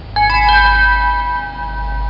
Chime Sound Effect
Download a high-quality chime sound effect.
chime.mp3